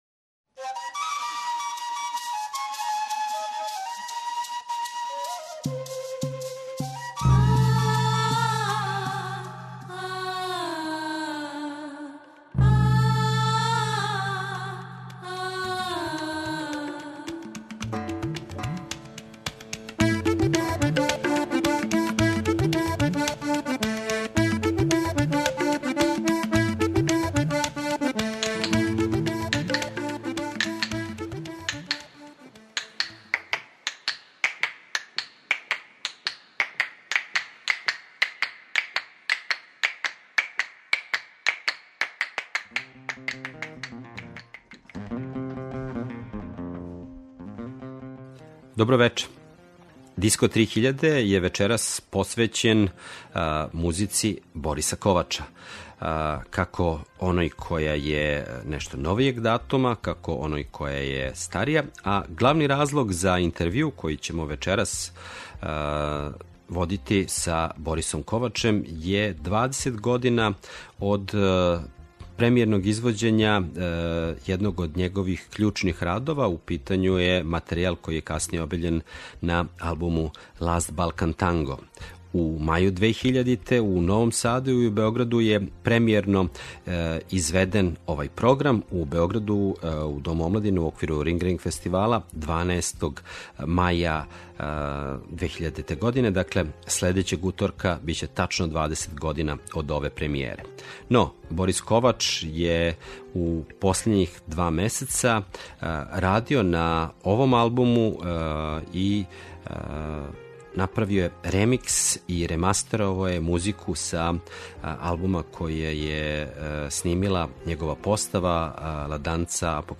Интервју са Борисом Ковачем поводом двадесет година премијере пројекта „The Last Balkan Tango”